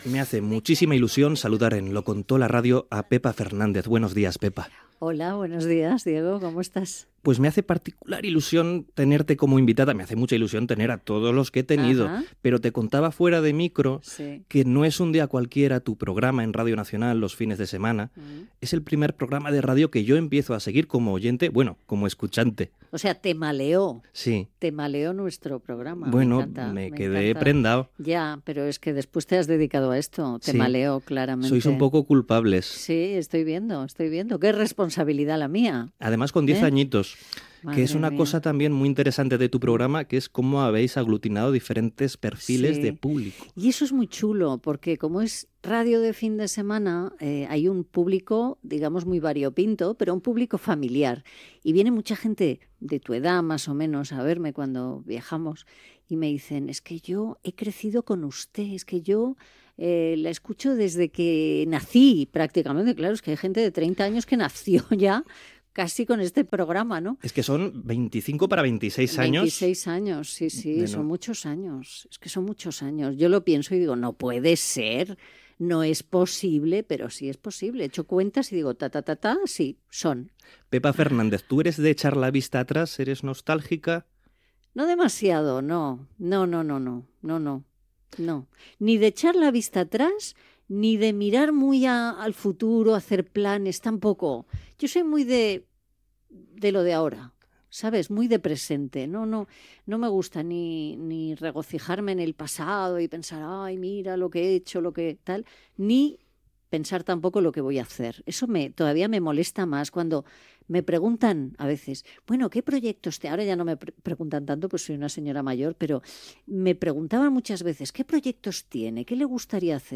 Fragment d'una entrevista a Pepa Fernández presentadora de "No es un día cualquiera" a RNE.
Entreteniment